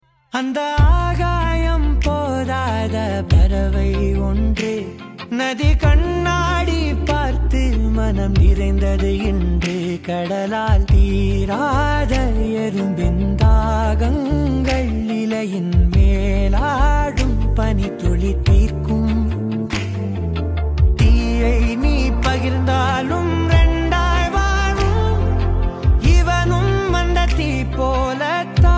soulful voice
crafting a melody that’s both uplifting and deeply resonant.